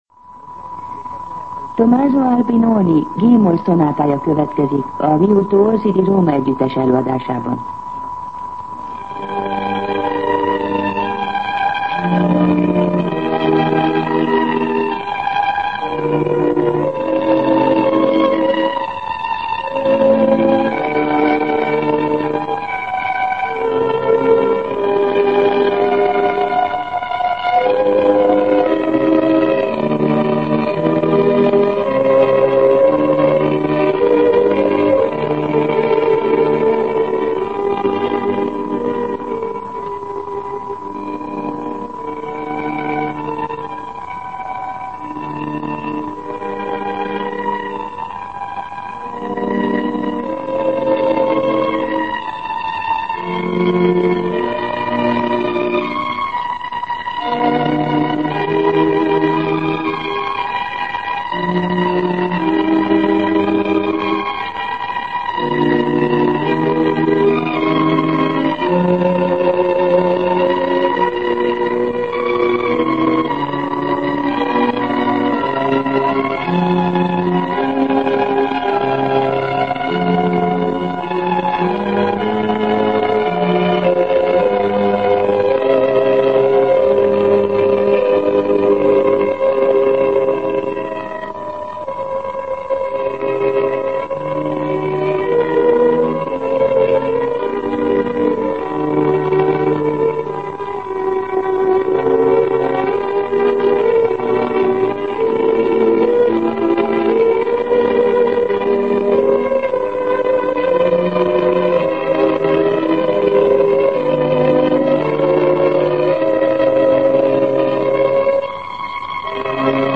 Zene
Itt a Szabad Európa Rádiója, a Szabad Magyarország Hangja.